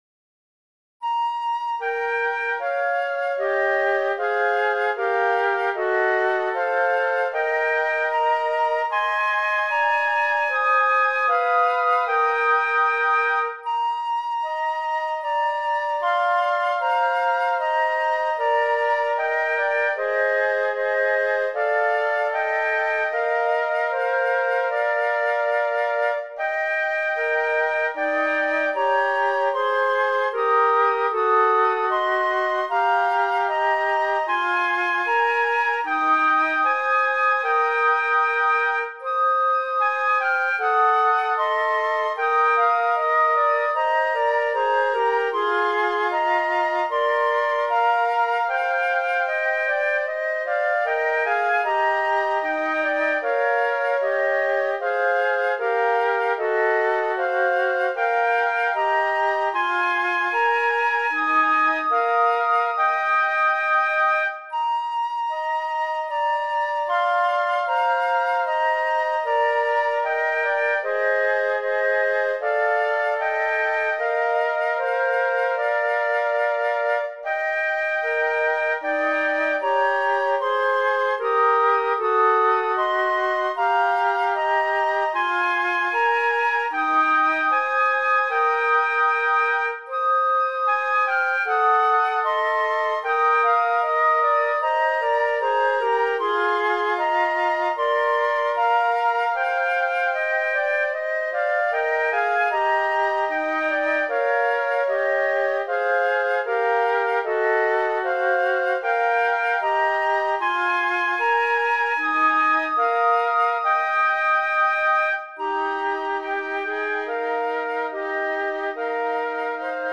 歌の出だしが分かりやすいイントロ付きです。後奏もあります。
スッキリ構造で一緒に歌ってもらうのに最適です。
かといって、演奏だけでも美しく聴いてもらえるようした（つもり）です。